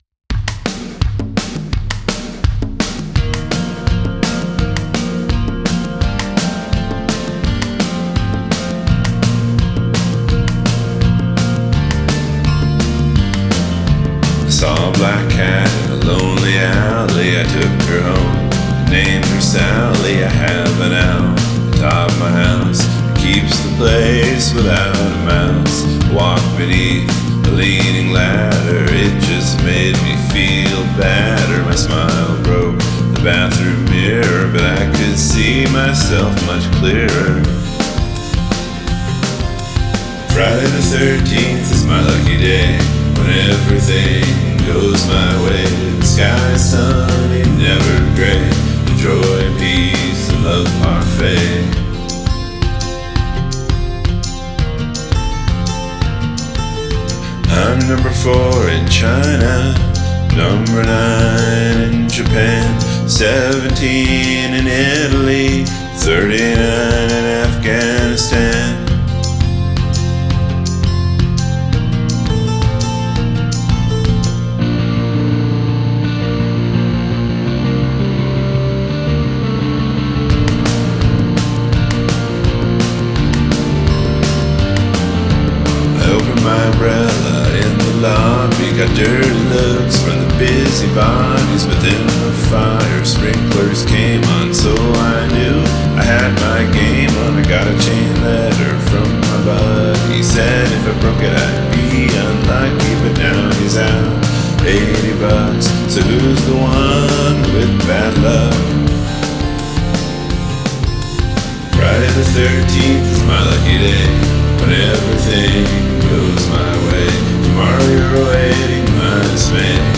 write a feel-good song with happy lyrics and upbeat music